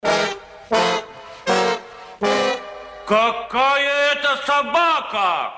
из фильмов
смешные